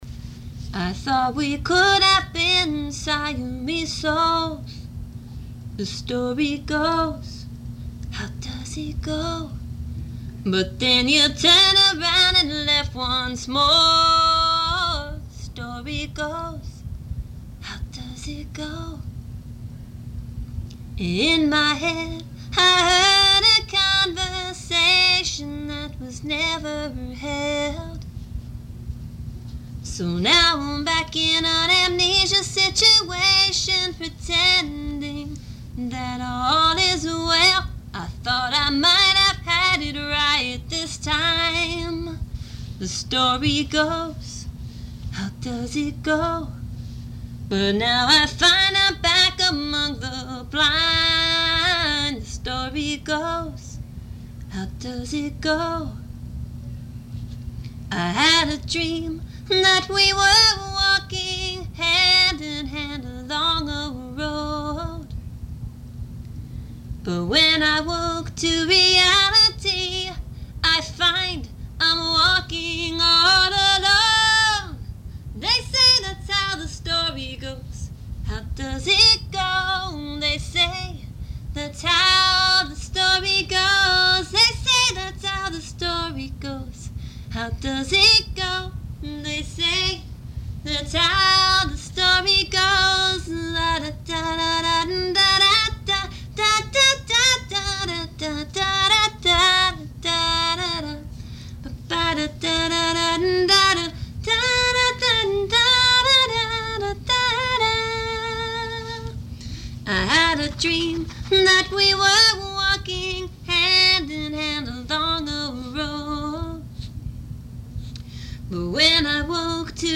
Unproduced practice tape a cappella